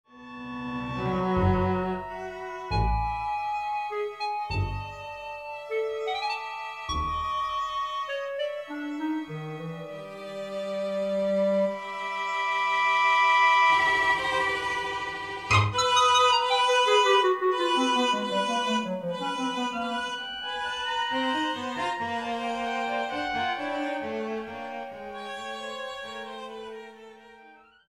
audio 44kz stereo